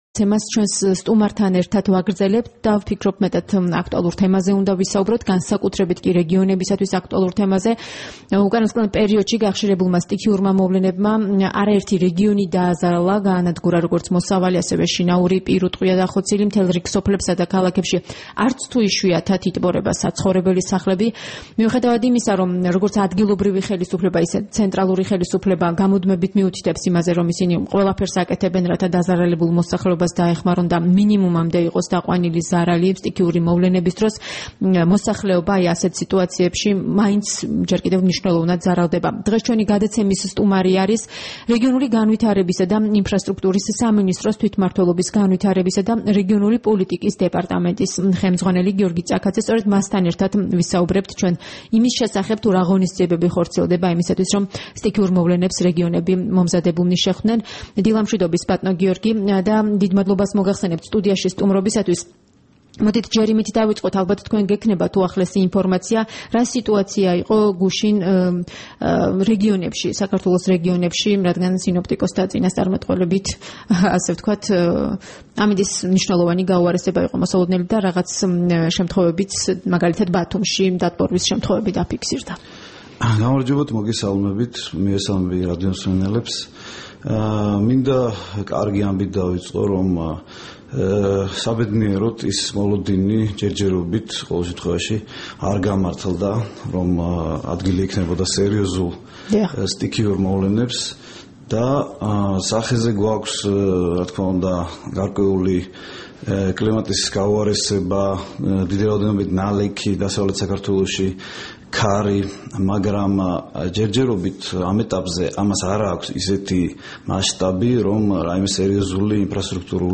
საუბარი